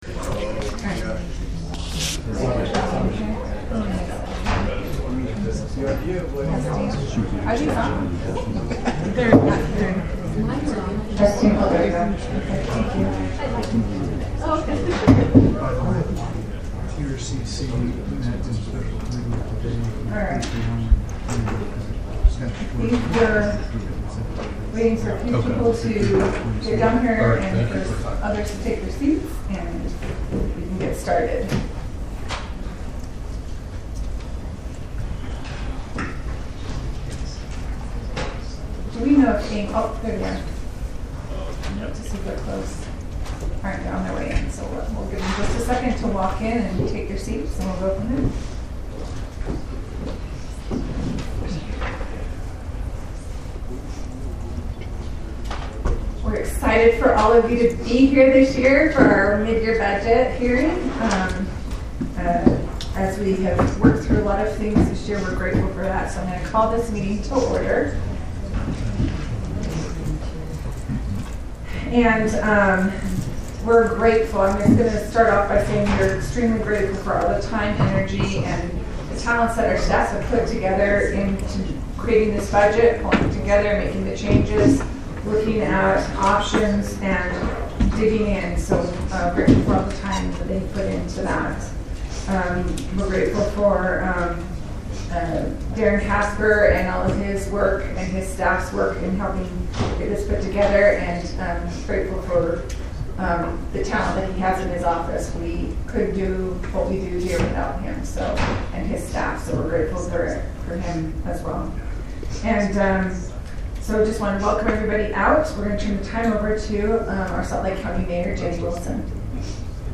Members Of The Council May Participate Electronically.